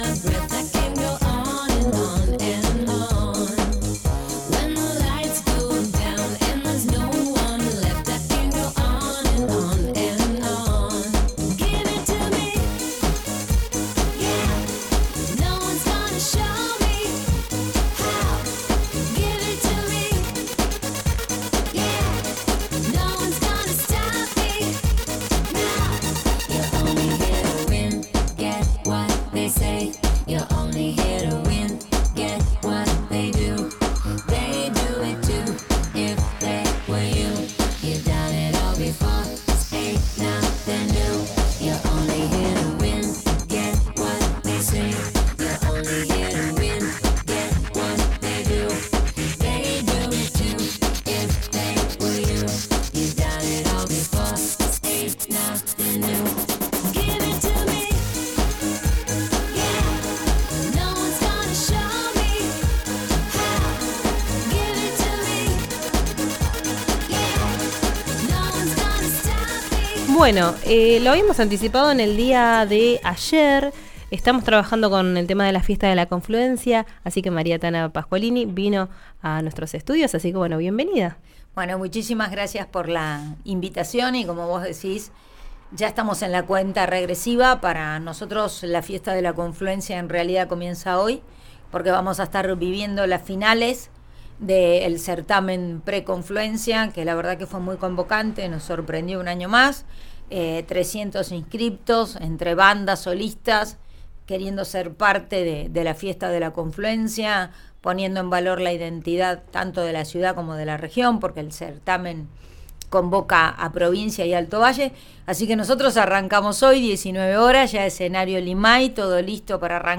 Escuchá a la jefa de gabinete María Pascualini en RÍO NEGRO RADIO
En una entrevista con RÍO NEGRO RADIO, la funcionaria se mostró exaltada por la nueva edición de la Fiesta de la Confluencia 2025, que aseguró será «excelente», como en sus otras oportunidades.